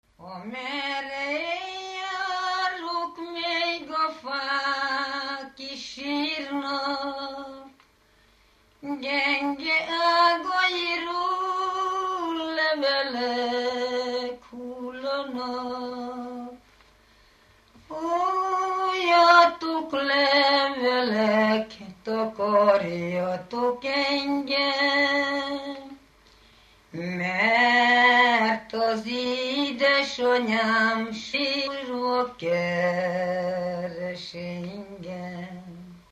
ének
Stílus: 4. Sirató stílusú dallamok
Kadencia: 5 (4) 5 1